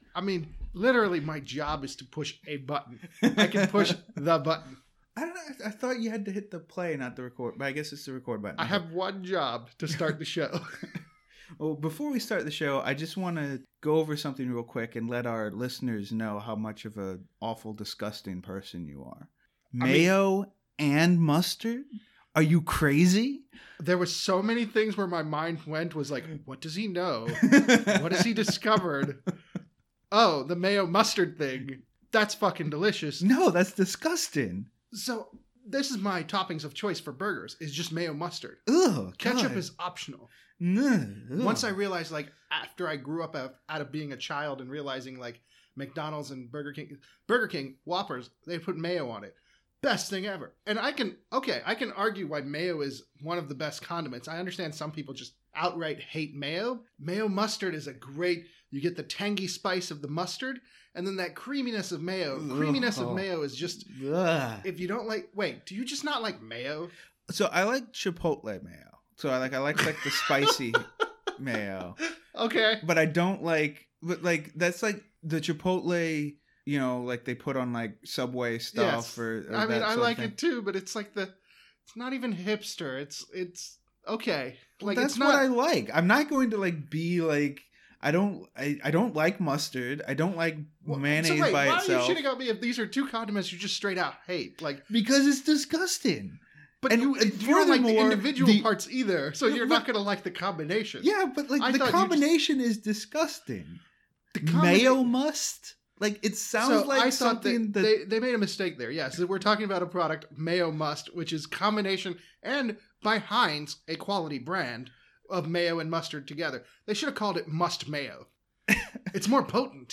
*NOTE: Due to a technical oversight, the last part of this episode was cut off and so it ends kind of abruptly.